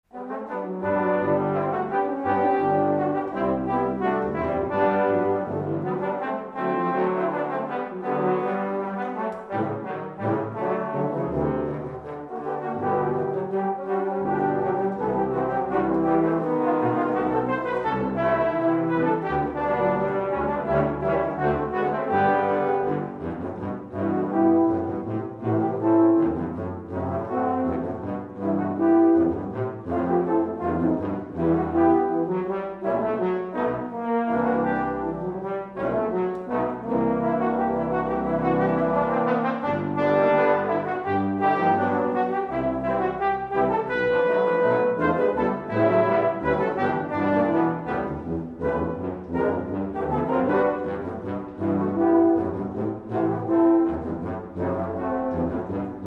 música académica costarricense